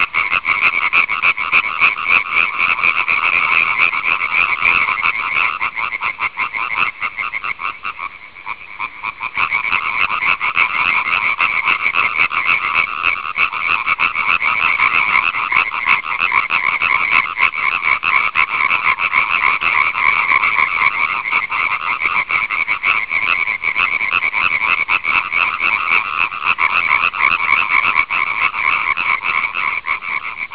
In giro per il Lazio:
30 Le raganelle hanno cominciato a "
canto raganella.wav